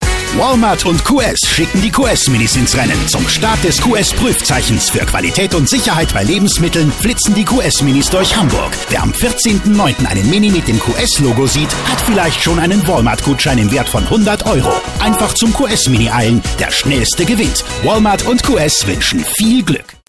versierter und stimmfarbenreicher Sprecher, markanter Off, verlässlicher Commercial, auch viele Dialekte, Akzente und Trickstimmen